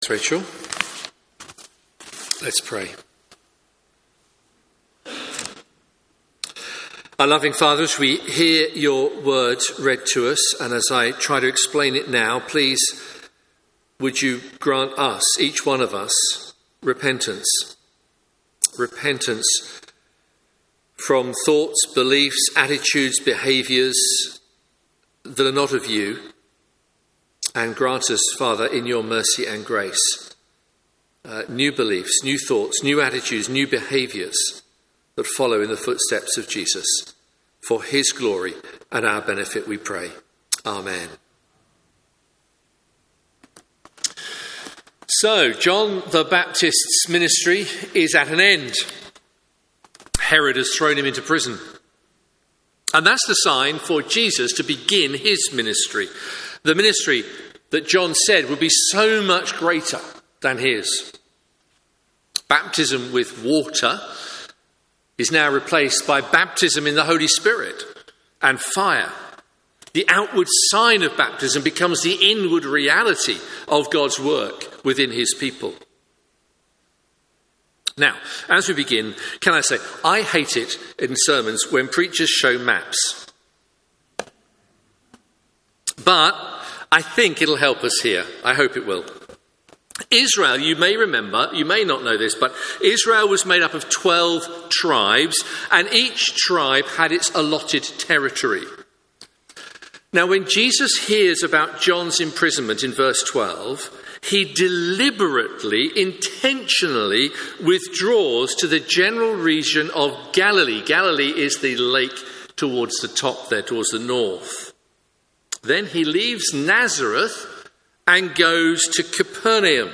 Media for Morning Service on Sun 17th Dec 2023 10:30 Speaker
Theme: Sermon In the search box please enter the sermon you are looking for.